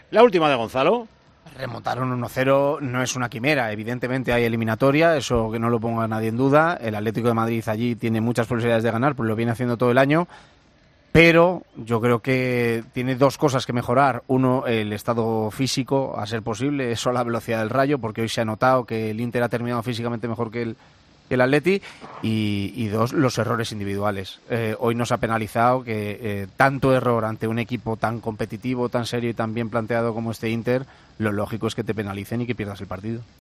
El comentarista de los partidos del Atlético de Madrid en Tiempo de Juego reflejó los dos detalles que le chirriaron y que condenaron al Atlético en Milán ante el Inter.